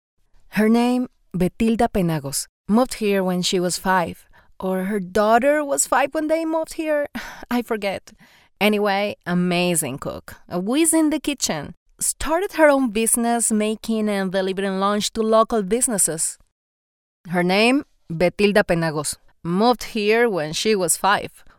Latin American voice over English accent